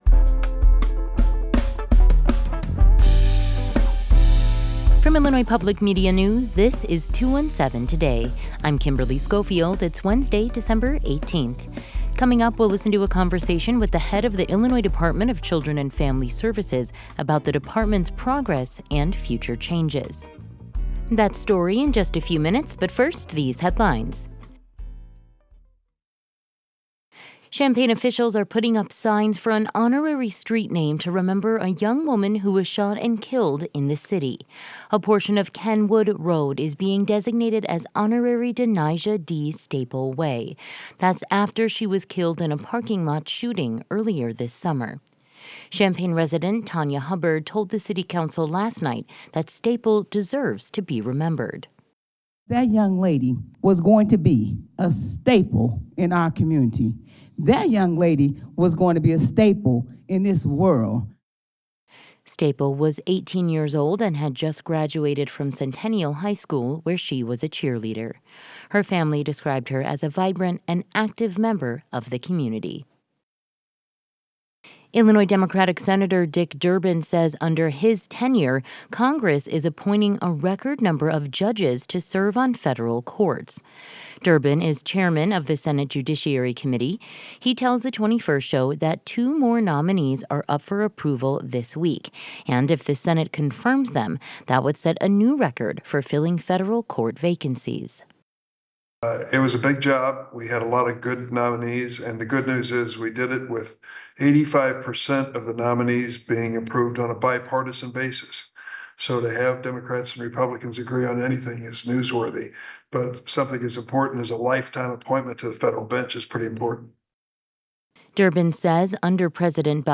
In today's deep dive, we'll listen to a conversation with the head of the Illinois Department of Children and Family Services about the department's progress and future changes.